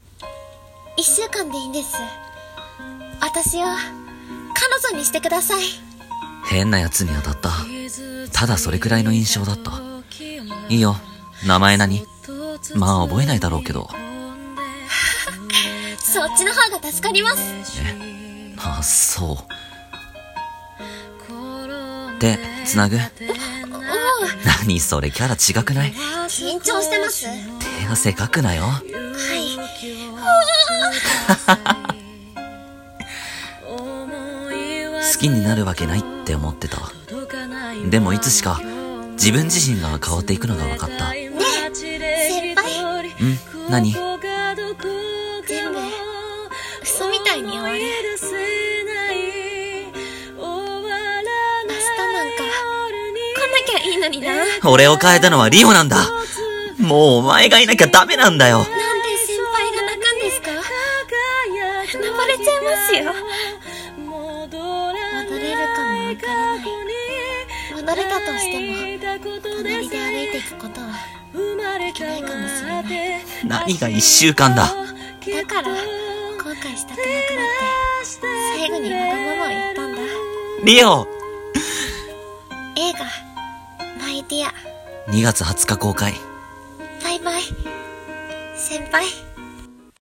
【予告風声劇】My dear,【台本】